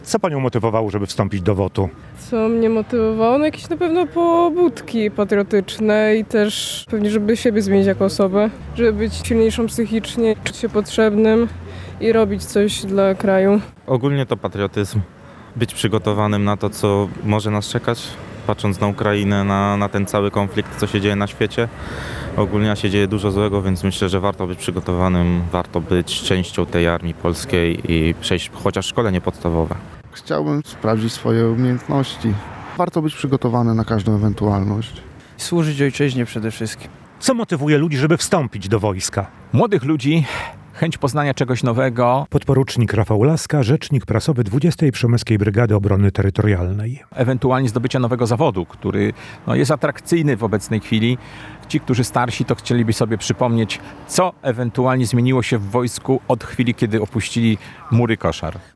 Tym razem odbyło się w 202. Batalionie Obrony Pogranicza w Jarosławiu.
Wcielenie-do-WOT-w-Jaroslawiu.mp3